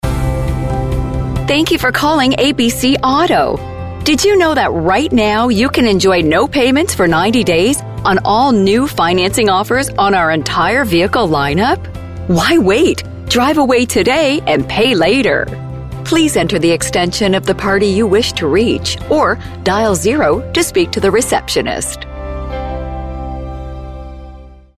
Inbound Call Promo takes the traditional auto attendant greeting a step further by incorporating a brief, up-front marketing message that is heard by every single caller that dials your number.
GenericAutomotiveAutoAttendantDemoWithInstruction.mp3